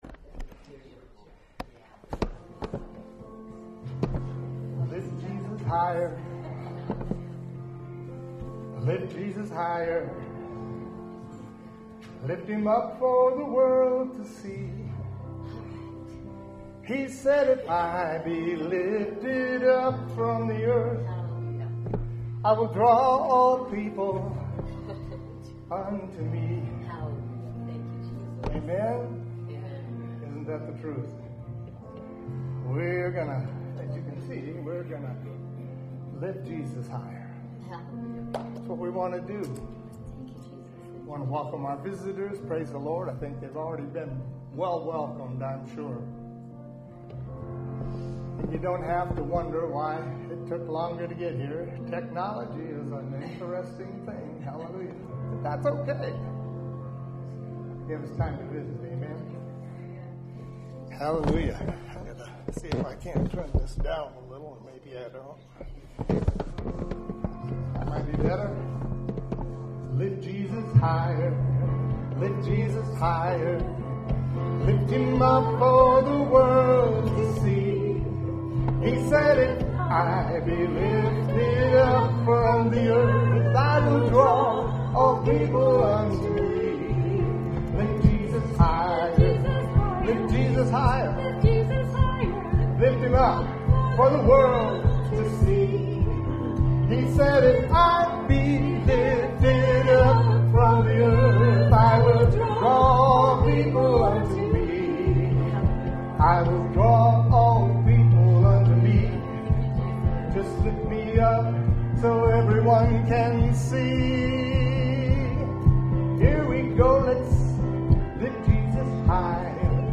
WORSHIP0118.mp3